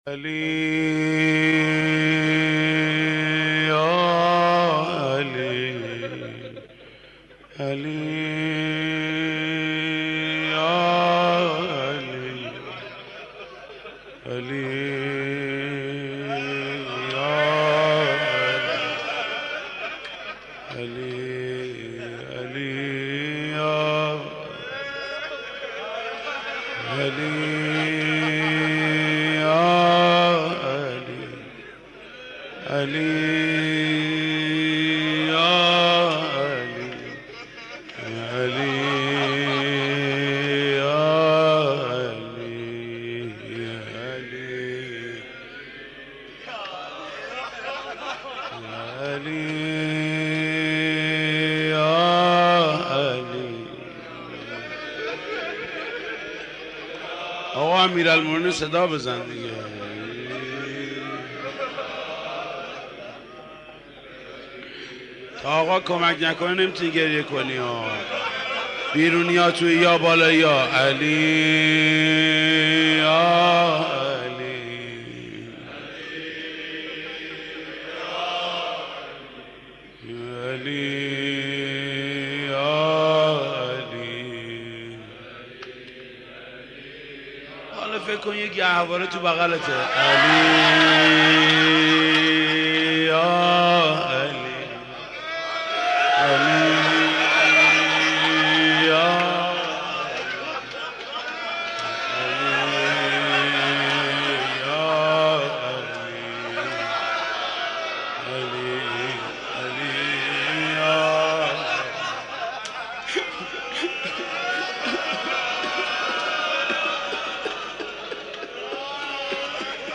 مداح
مناسبت : شب هفتم محرم
مداح : محمود کریمی